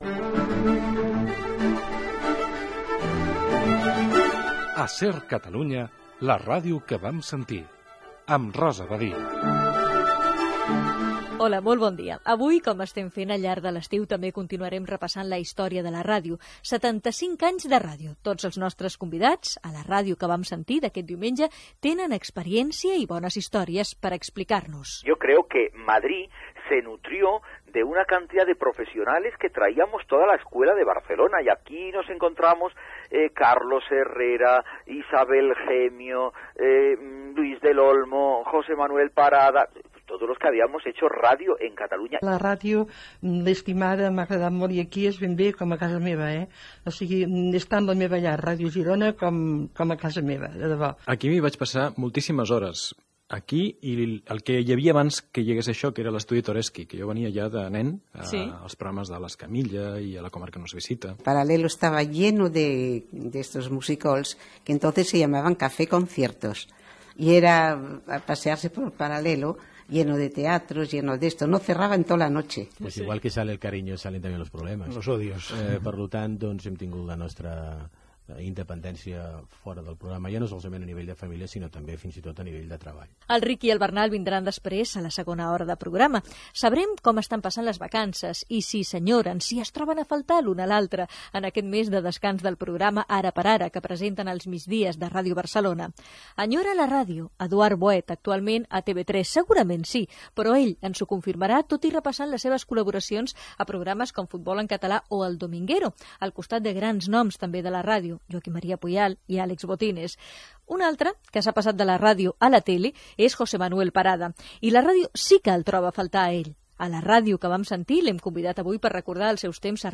Careta del programa, recull de veus, sumari, equip, indicatiu, entrevista al presentador José Manuel Parada.